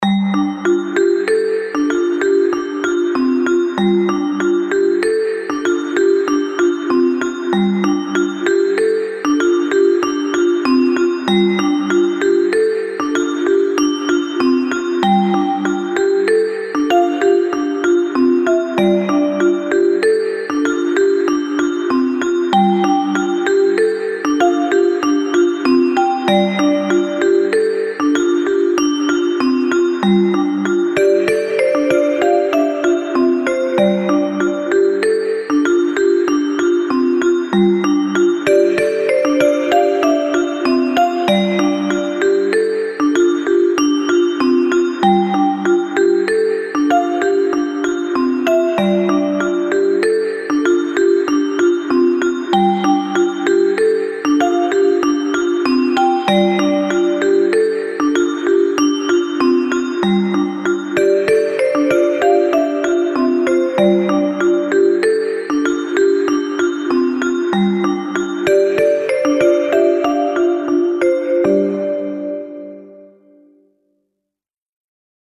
ほのぼのとした癒し系のBGMが多いです。
少し緊張感のある、淡々とした曲。